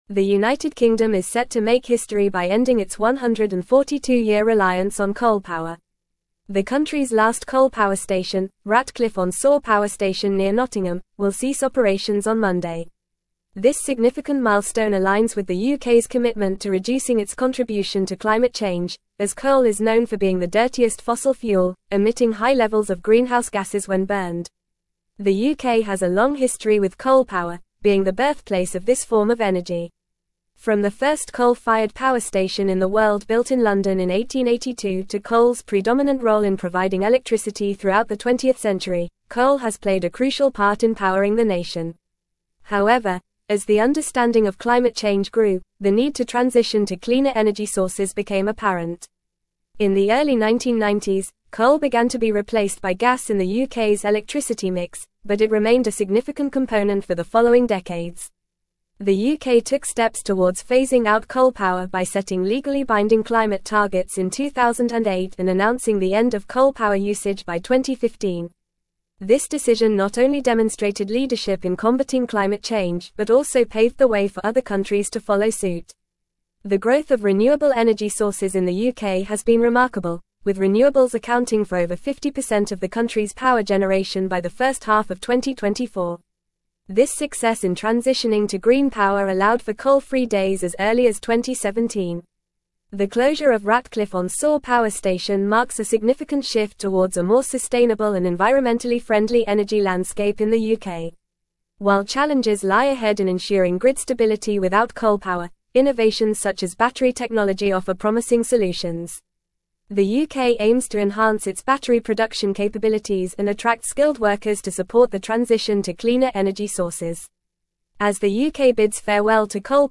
Fast
English-Newsroom-Advanced-FAST-Reading-UK-Ends-142-Year-Coal-Reliance-Shifts-to-Renewables.mp3